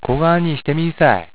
ためになる広島の方言辞典 か．